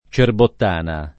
cerbottana
cerbottana [ © erbott # na ] s. f.